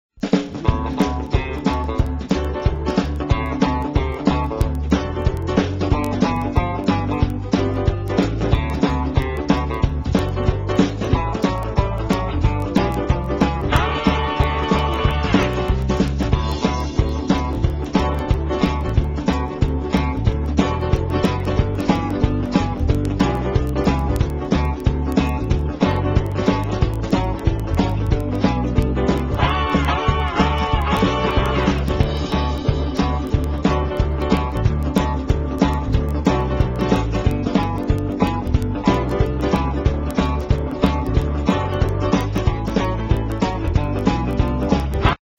BLUES KARAOKE MUSIC CDs
NOTE: Background Tracks 9 Thru 16